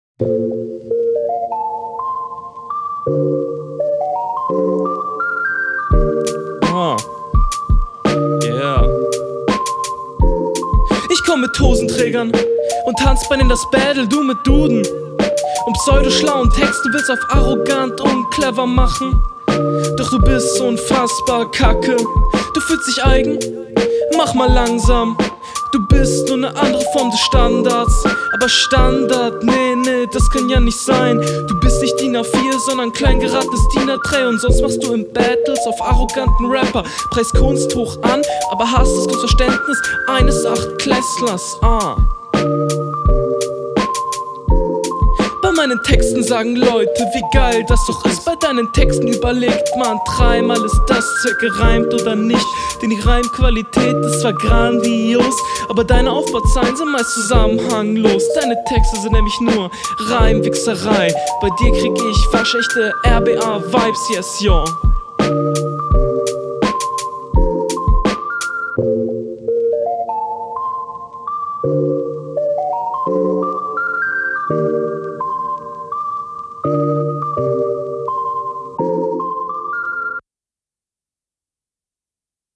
Soundqualität ist definitiv vorhanden - alles sauber verständlich.
Sehr entspannter Beat. Ich mag den lateback Flow auch wenn er nicht immer clean ist.